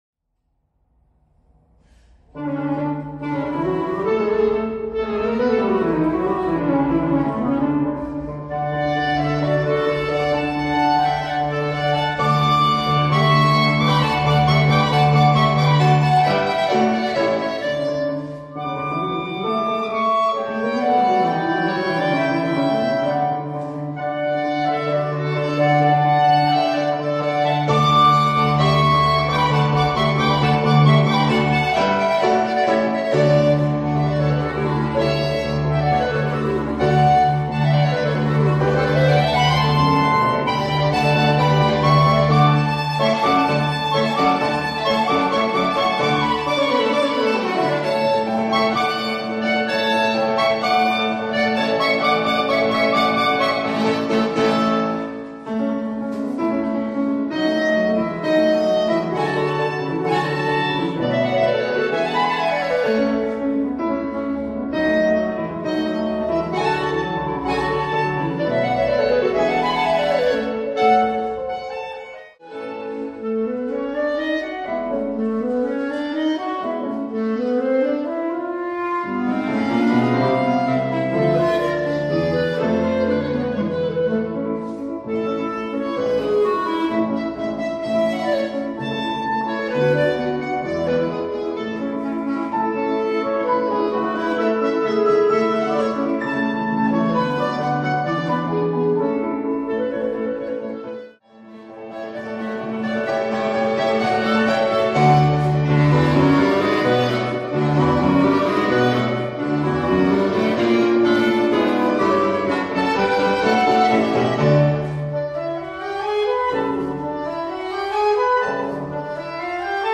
编制：Cl / Cl / Pno
A Clarinet 1 (*B♭ Clarinet 1)
A Clarinet 2 (*B♭ Clarinet 2)
Piano
演奏要点在于要明确表现从弱音(p)到强音(f)的力度变化。